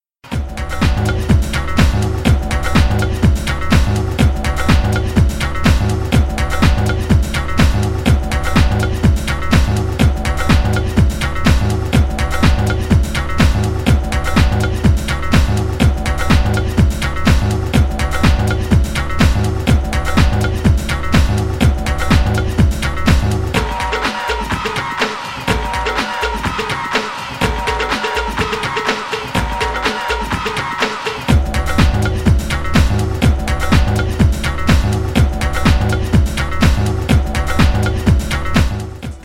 Lighthearted and aqua-marine house grooves